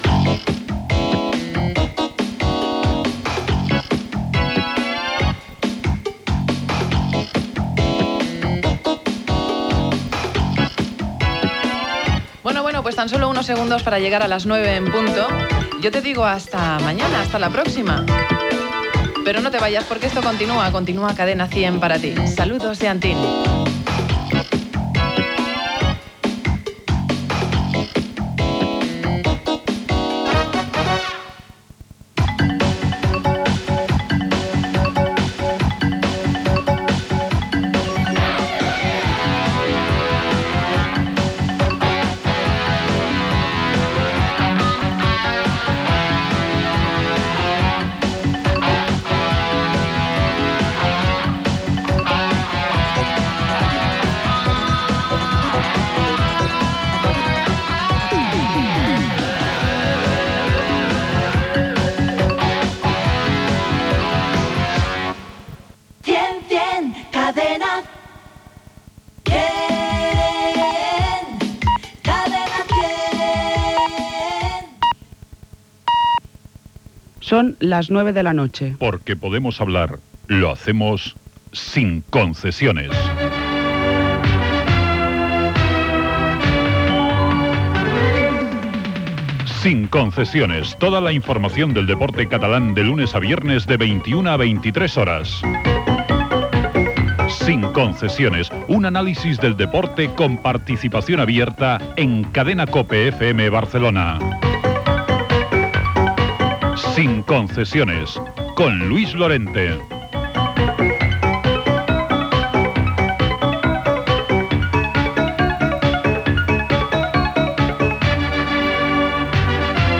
Comiat de la programació de la Cadena 100 amb indicatiu i inici del programa esportiu "Sin concesiones".
Esportiu
FM